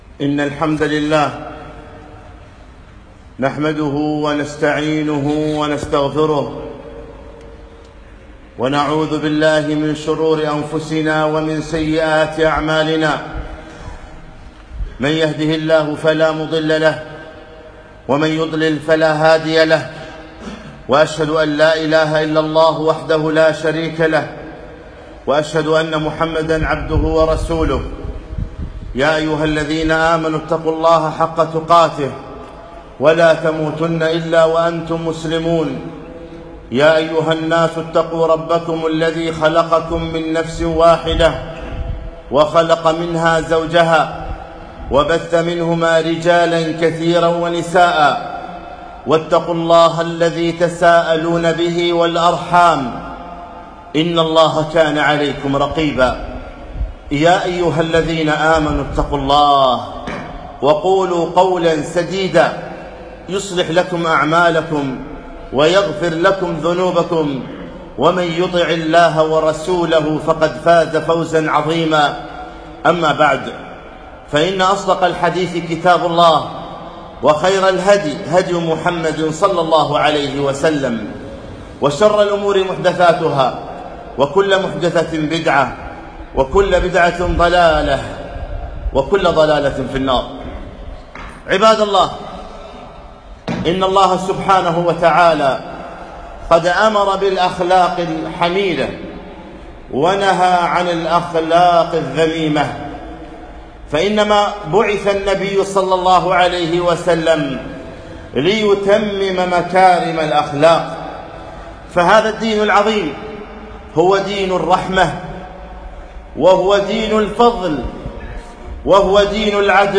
خطبة - خطورة الغضب